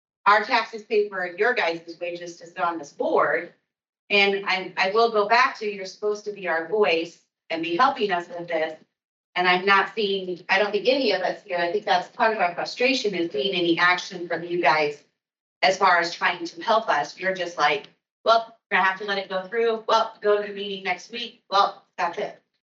(Guthrie Center, IA) — The Guthrie County Board of Supervisors had a packed house on Tuesday morning and residents of Guthrie County at times got emotional against the Summit Carbon Solutions Pipeline